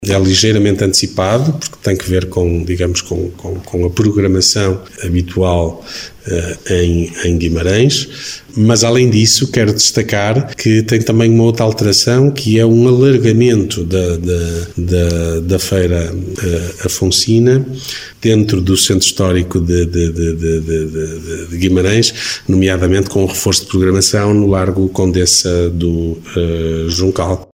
Ricardo Araújo, presidente da Câmara Municipal de Guimarães, que acrescenta que a antecipação da data da Feira Afonsina pretende não estar a sobrepor iniciativas, e alargar o programa das celebrações do Dia Um de Portugal, que se assinala a 24 de junho.